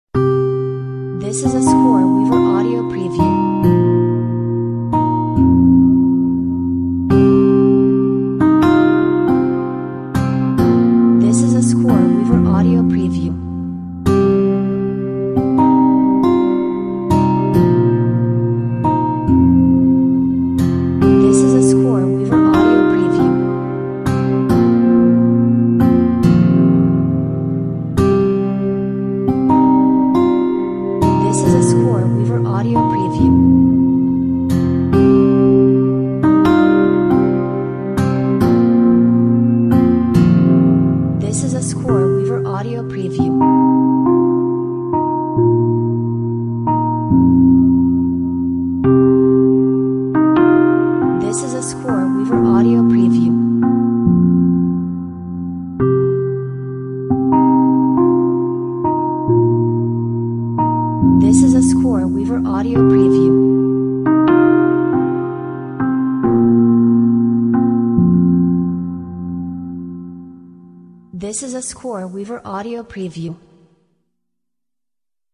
Deep guitar with soft strings create a somber mood!